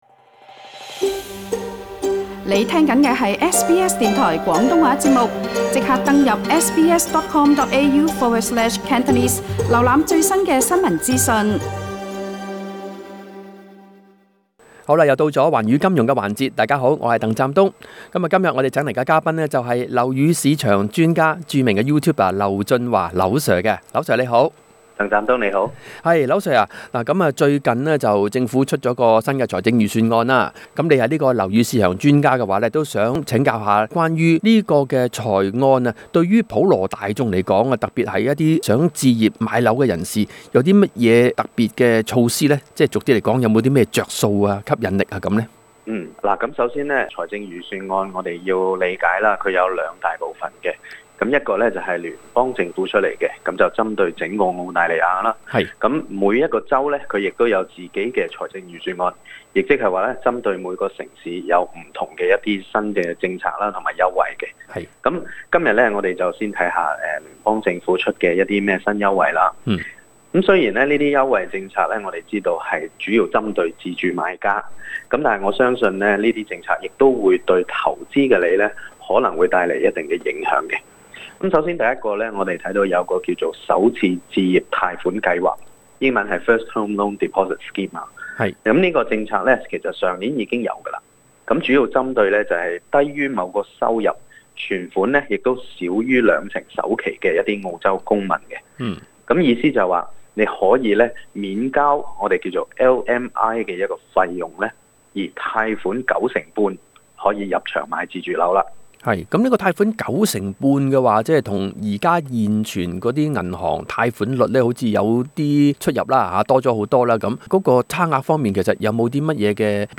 訪問樓宇市場專家